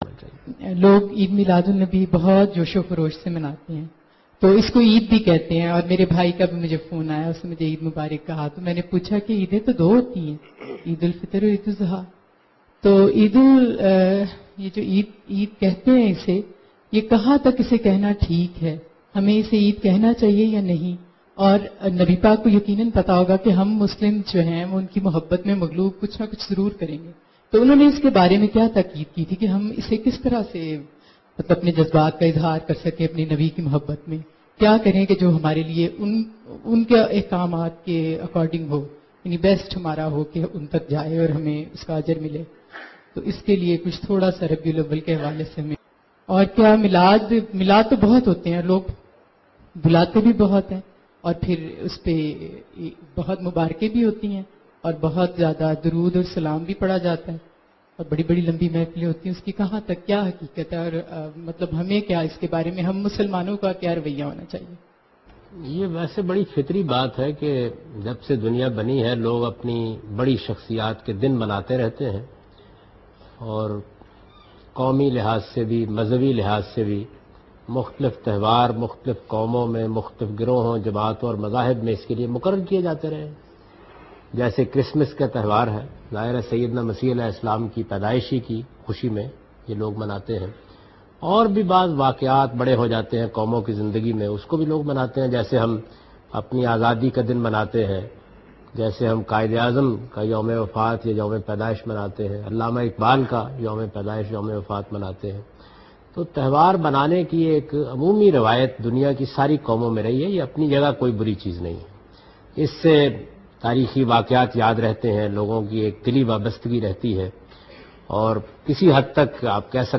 Javed Ahmad Ghamidi Answering a question regarding birthday of Holy Prophet Muhammad (sws).